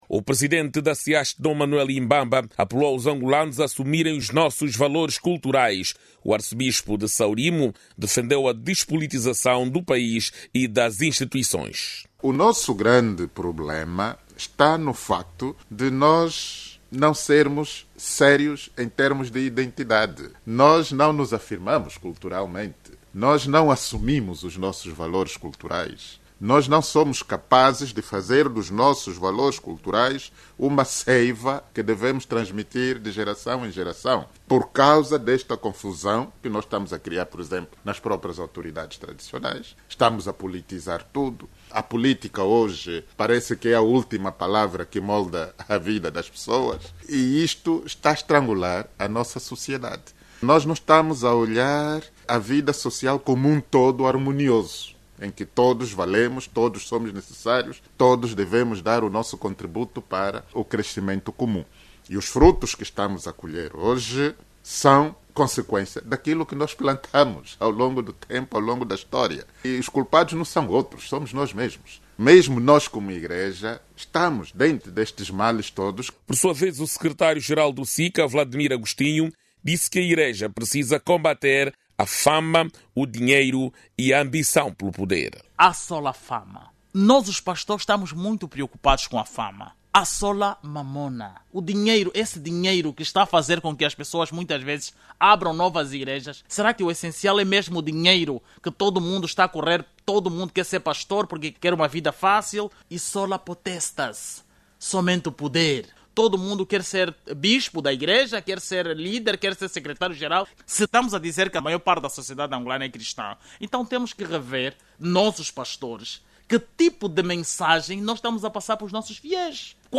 O resgate dos valores cívicos e patrióticos depende do envolvimento de todos os angolanos, independentemente do seu credo religioso ou filiação política.  Esta é a conclusão a que chegaram os participantes ao debate Angola em Directo, da Rádio Nacional de Angola que analisou o contributo da igreja no resgate dos valores morais, cívicos e patrióticos. A liderança religiosa angolana defende a despolitização das instituições.